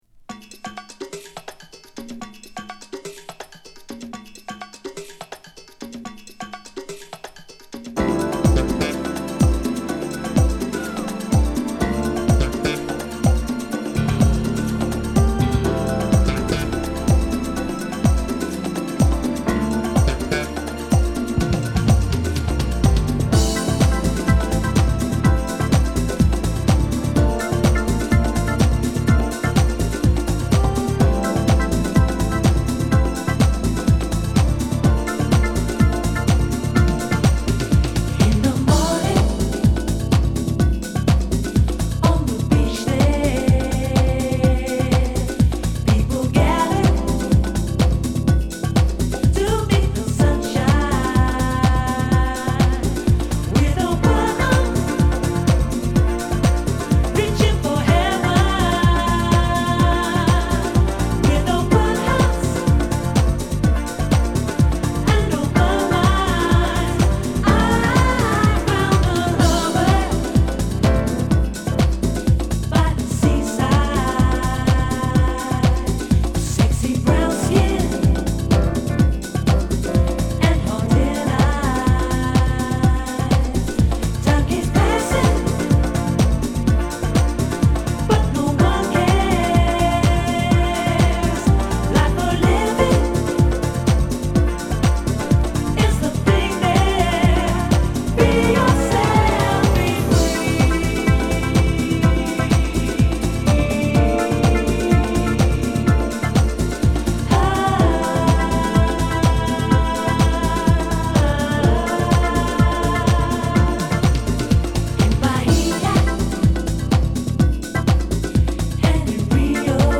UKのジャズファンクバンド
＊試聴はB2→Aです。